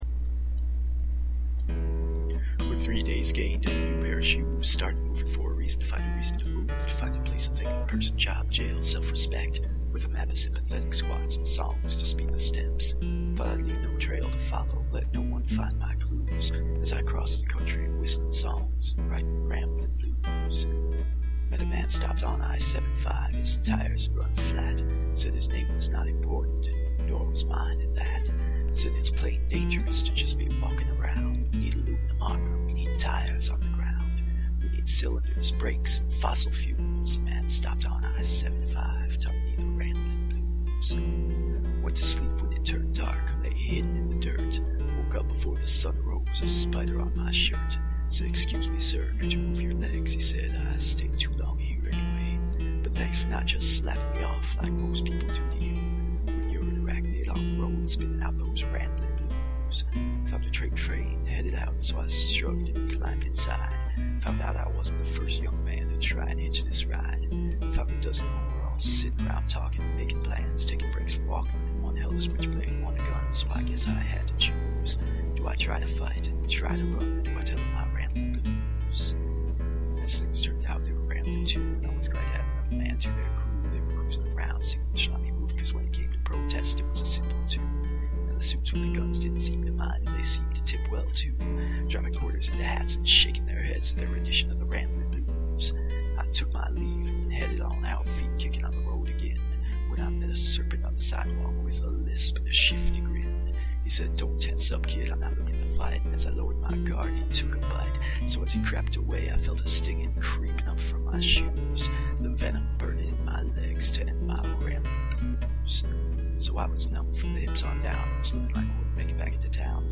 Track: 02 Kind: Poem Type: MP3 Time: 00:02:52 Size: 2.62 MB